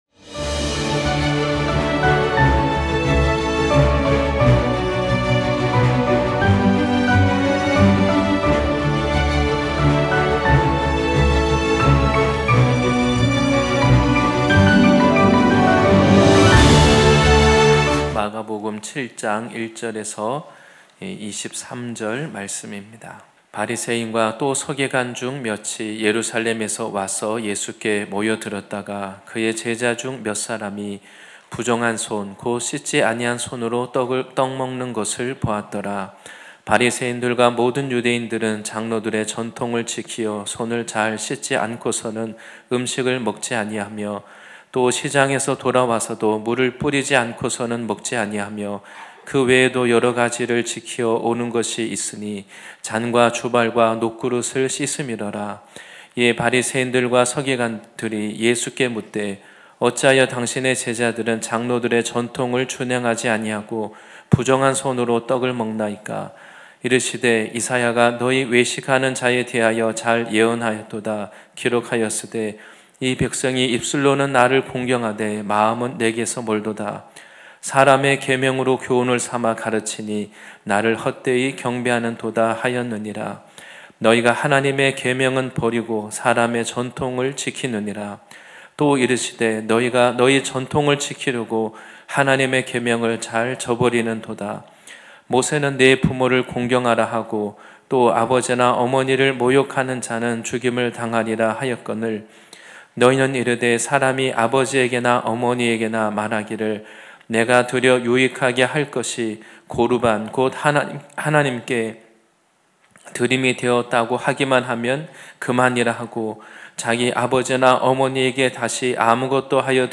2026년1월25일 주일예배말씀